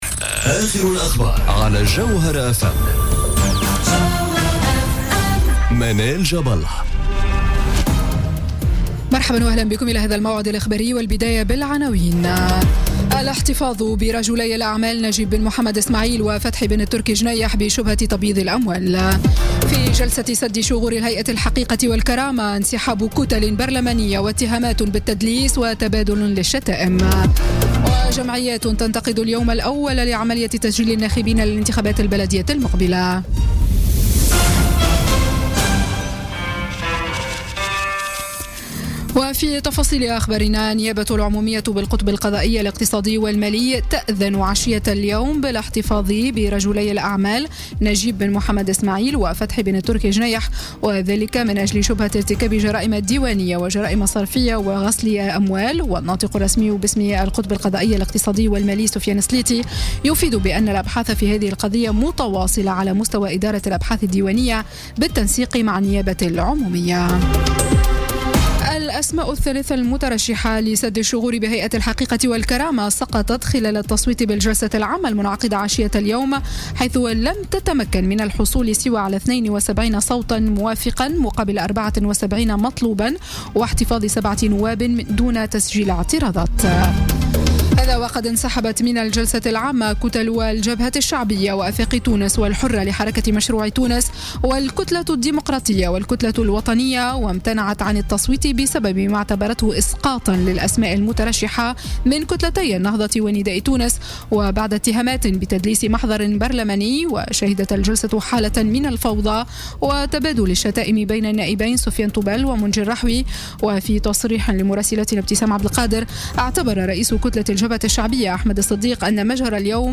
نشرة أخبار السادسة مساء ليوم الثلاثاء 20 جوان 2017